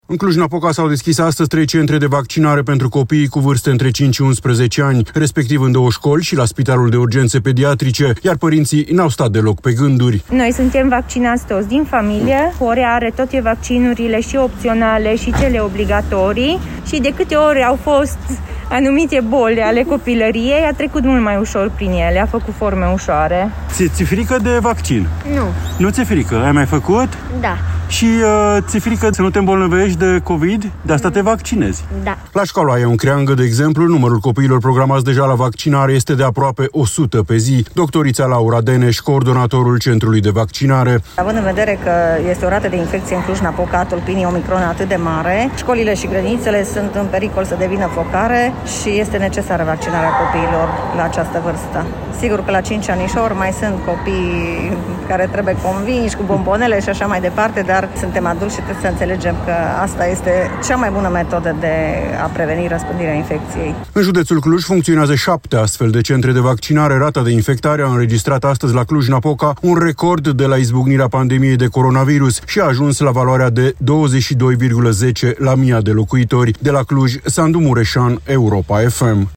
„Având în vedere că și noi ne-am vaccinat astăzi, chiar cu doza a treia, booster, am zis că poate ar fi mai bine și pentru el. Am considerat că e mai bine, și, deși am fost reticenți la început în privința vaccinului, am văzut că și lumea s-a înclinat în direcția vaccinării și acuma am trecut și noi la rând, deși nu suntem siguri sută la sută că e eficient. Copilul ascultă ce-i spunem noi”, a declarat tatăl unui copil.